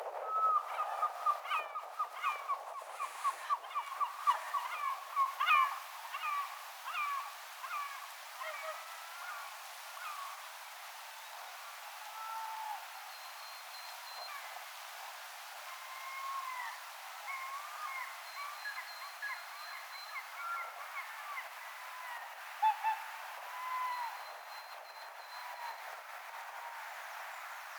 paikallinen harmaalokkipari?
paikallinen_harmaalokkipari_luulisin.mp3